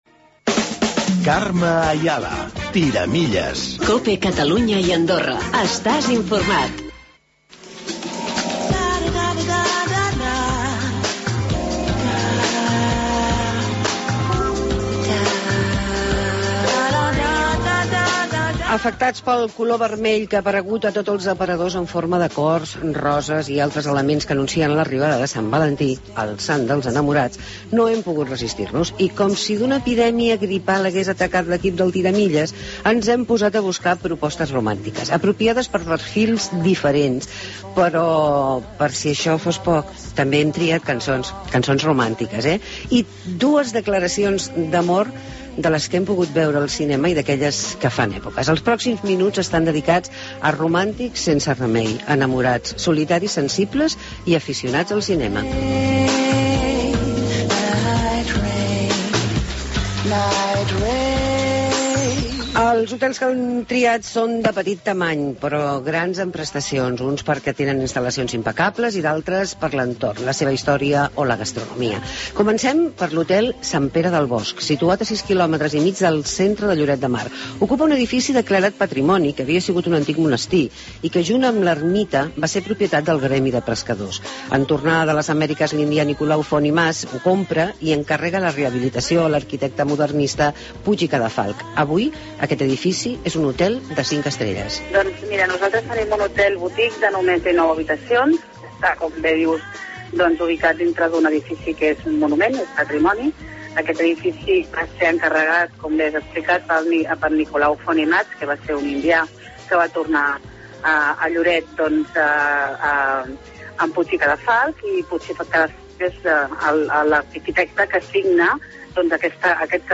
Reportatge Per San Valenti Grans Hotels Petits